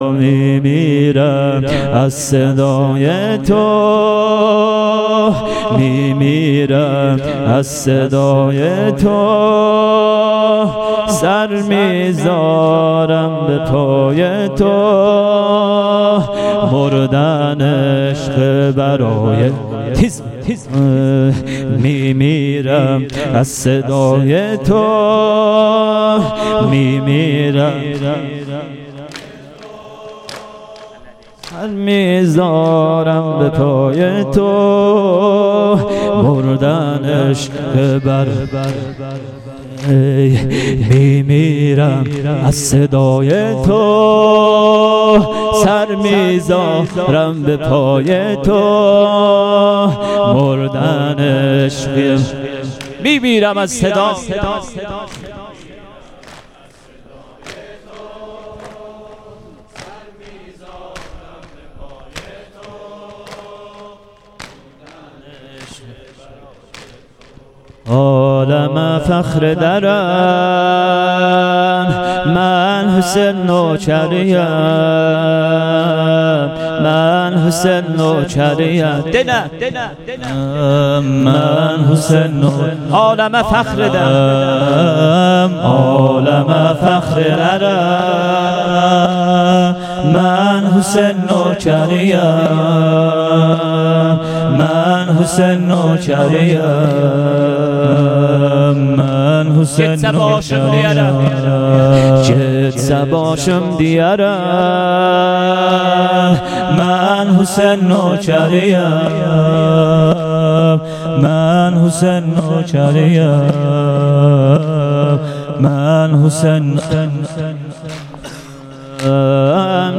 شب پنجم محرم 98 - بخش چهارم سینه زنی(تک)